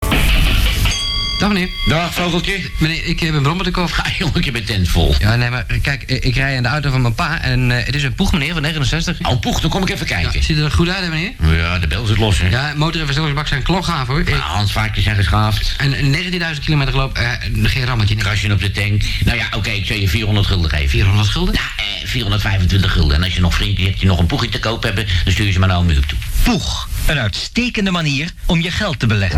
Oude Puch radio-reclame (ca. 1971).
puch-radio-reclame1971.mp3